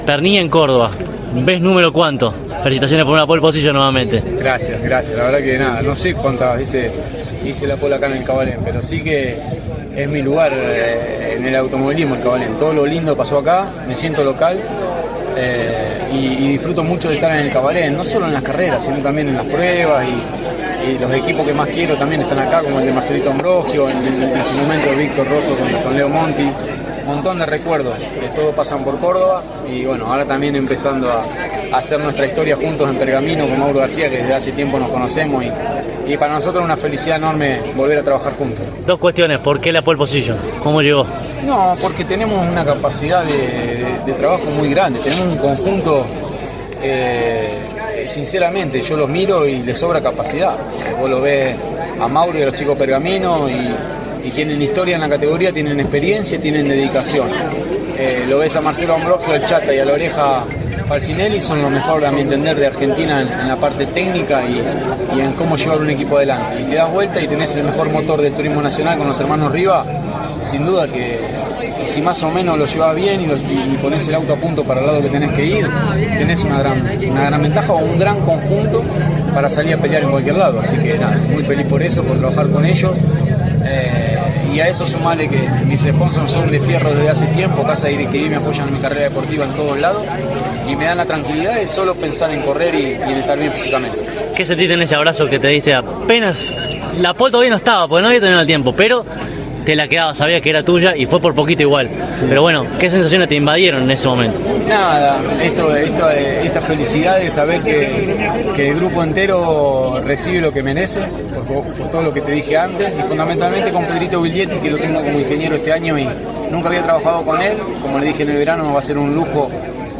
Por último, los tres protagonistas más importantes de la clasificación dialogaron con CÓRDOBA COMPETICIÓN y estos son sus testimonios:
Pernia-en-pole.mp3